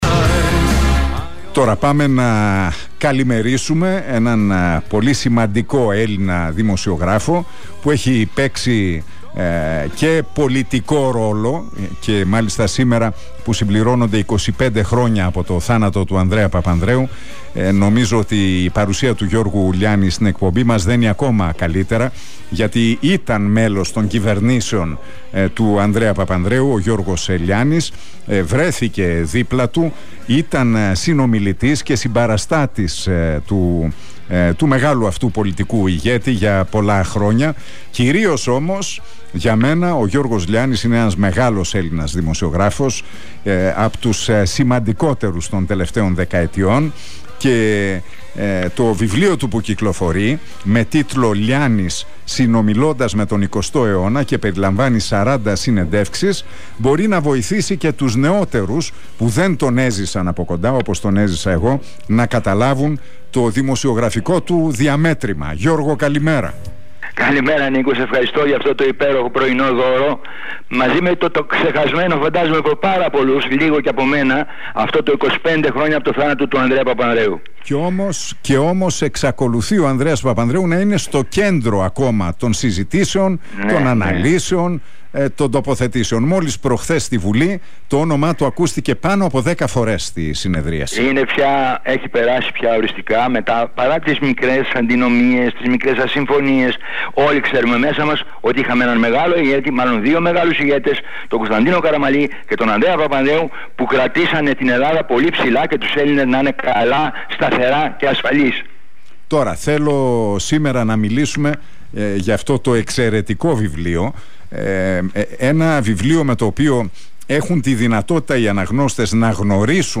Ο Γιώργος Λιάνης, μιλώντας στον Realfm 97,8 και στην εκπομπή του Νίκου Χατζηνικολάου...